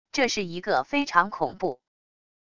这是一个非常恐怖wav音频